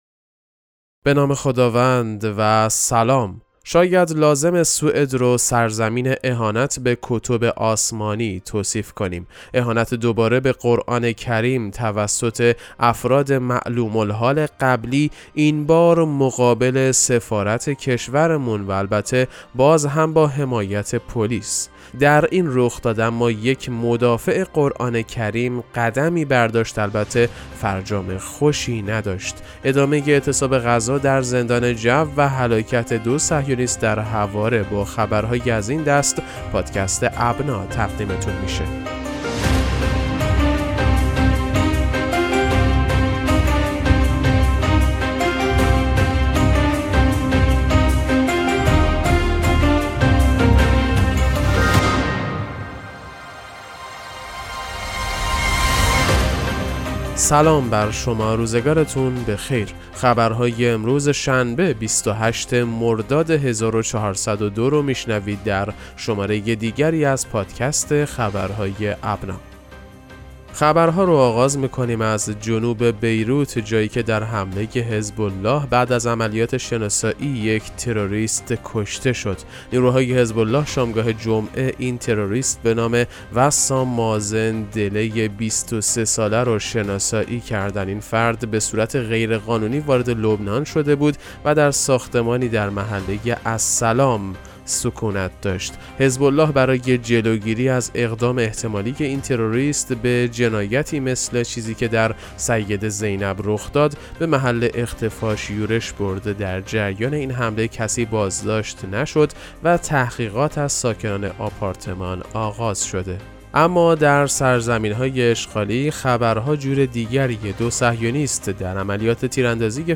پادکست مهم‌ترین اخبار ابنا فارسی ــ 28 مرداد 1402